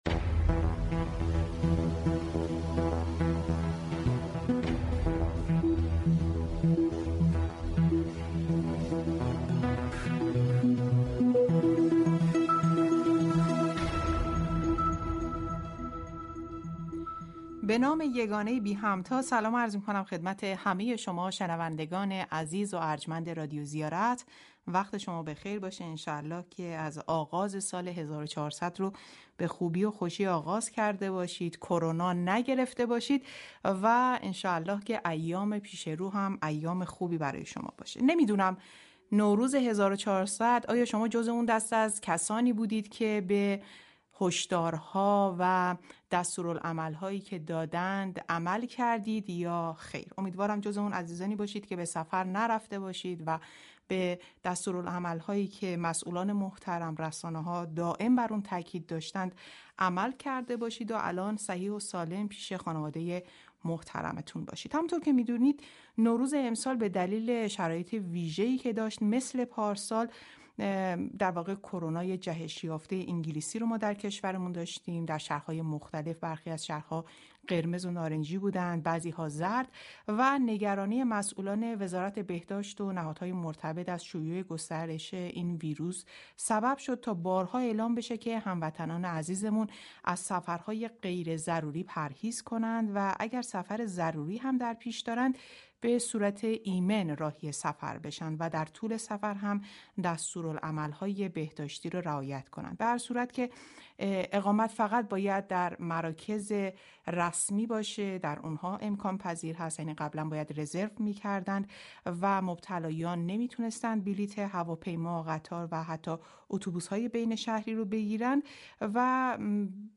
گفتگوی ویژه خبری رادیو زیارت به این موضوع پرداخت.